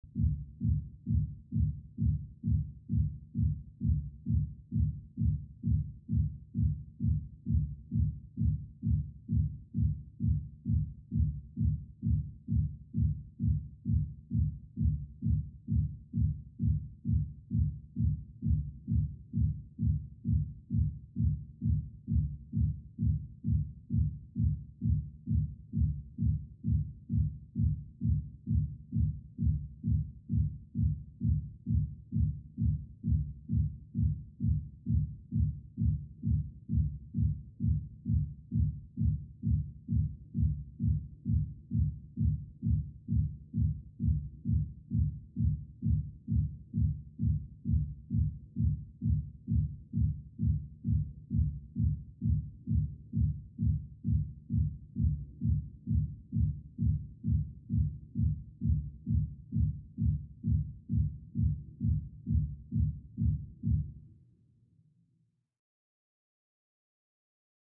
Music; Electronic Dance Beat, Through Thick Wall.